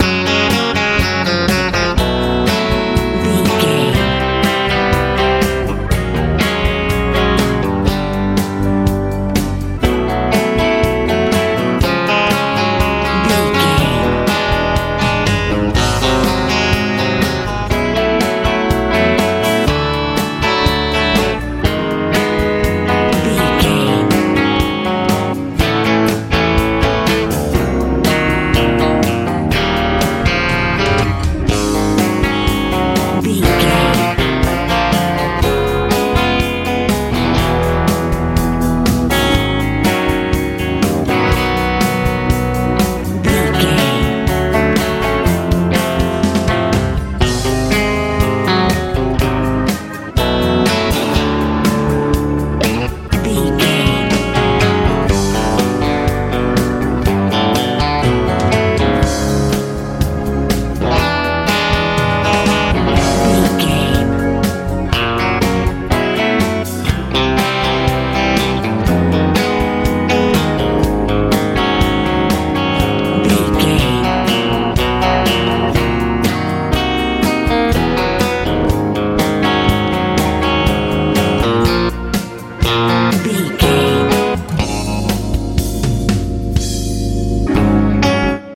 pop lite rock feel
Ionian/Major
F♯
dreamy
lively
acoustic guitar
electric guitar
organ
bass guitar
drums